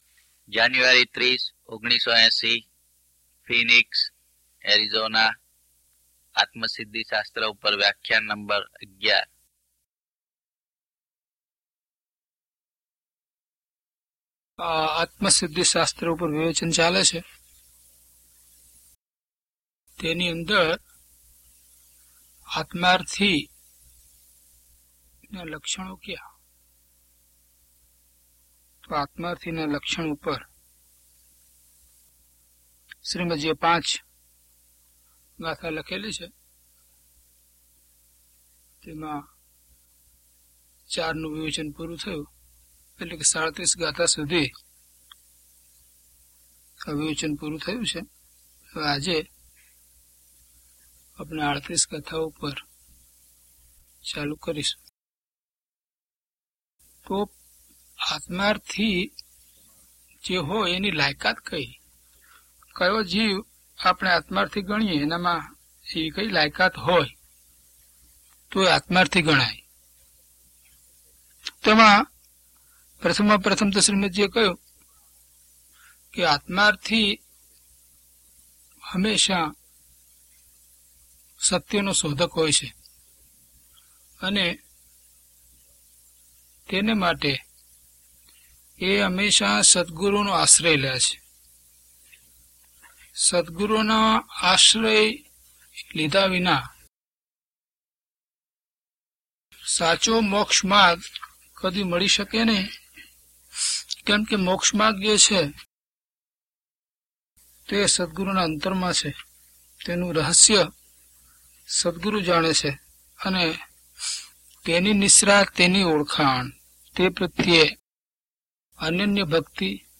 DHP020 Atmasiddhi Vivechan 11 - Pravachan.mp3